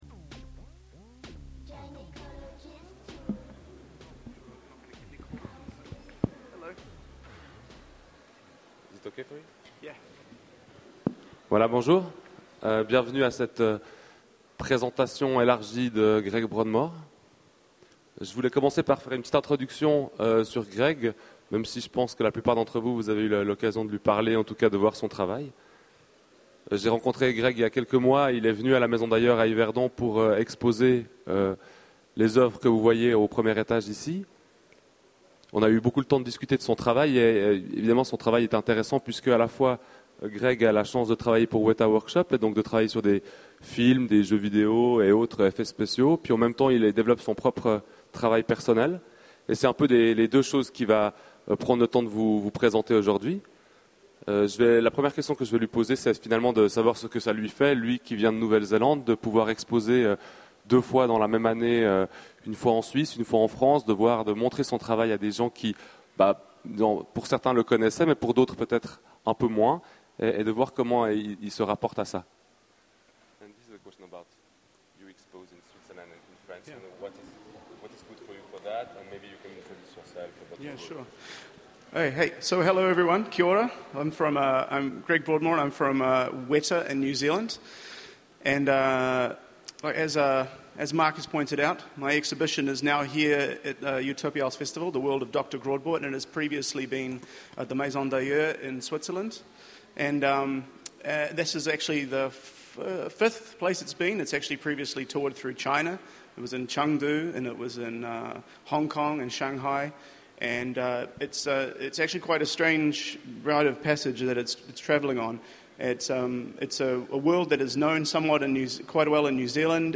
Utopiales 2011 : rencontre avec Greg Broadmore